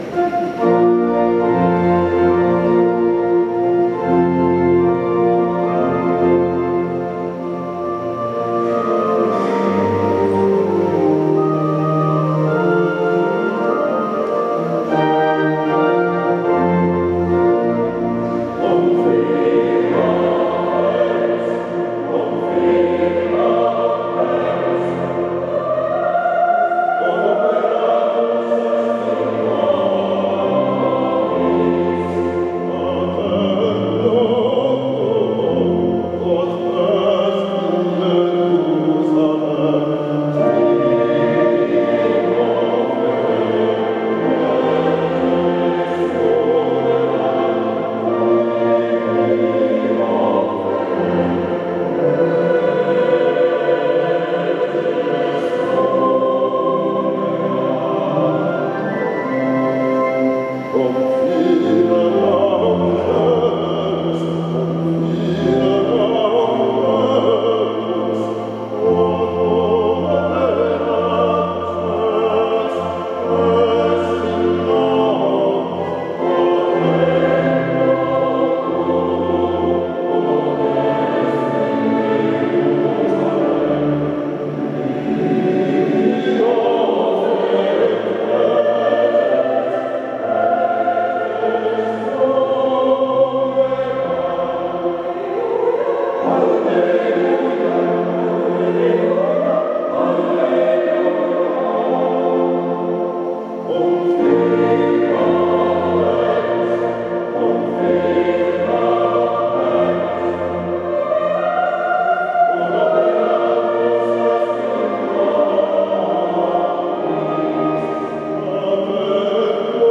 Un piccolo estratto dallo spettacolo “All’Aquila d’oro” tenuto a Valdobbiadene il 3 aprile 2016